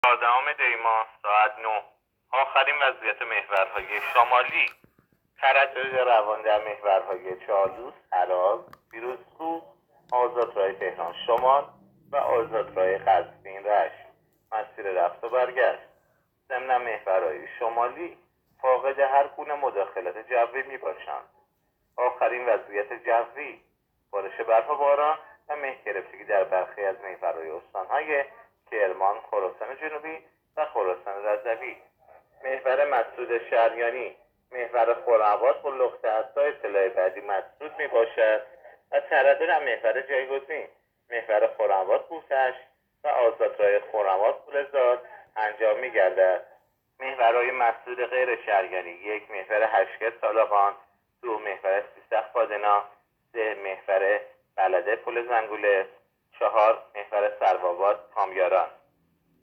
گزارش رادیو اینترنتی از آخرین وضعیت ترافیکی جاده‌ها تا ساعت ۹ چهاردهم دی؛